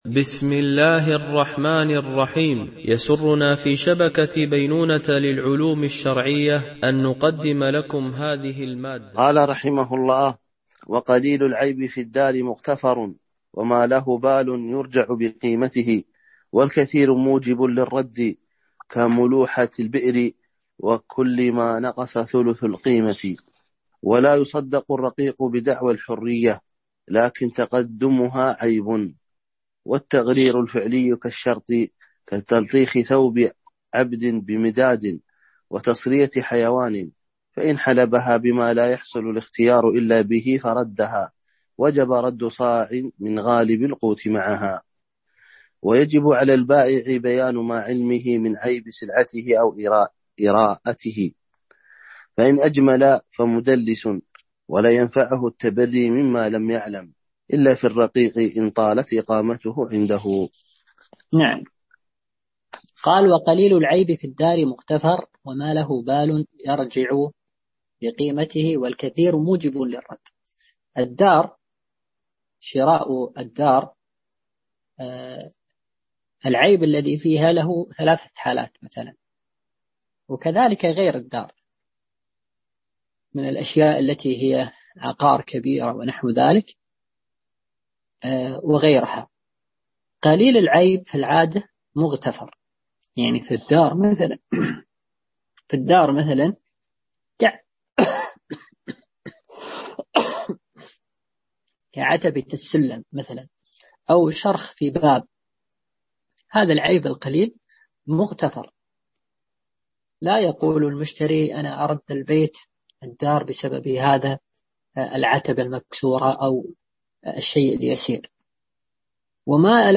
شرح الفقه المالكي ( تدريب السالك إلى أقرب المسالك) - الدرس 47 ( كتاب البيوع )